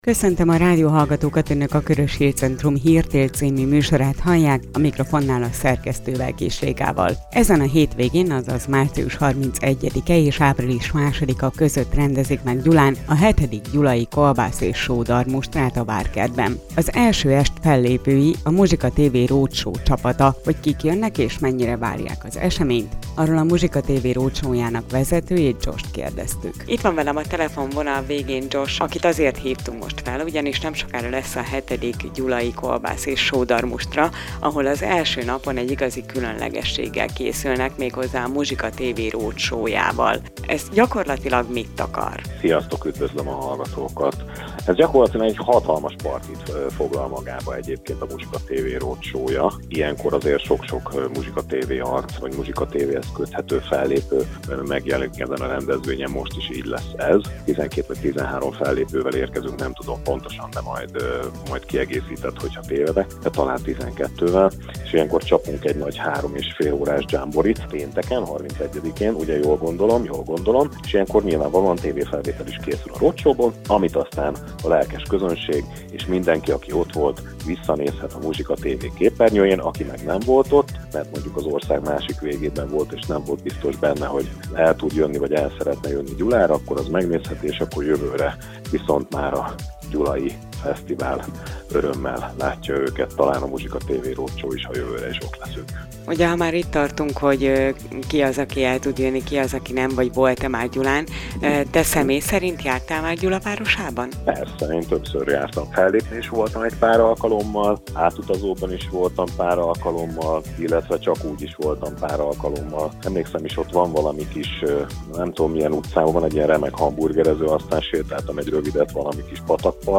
Post navigation Előző hír Az informatikáról és a földrajzról tanulnak legszívesebben Következő hír Várhatóan lesz folytatása a Reintegrációs Cafénak KATEGÓRIA: Interjúk